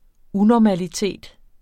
Udtale [ ˈunɒmaliˌteˀd ]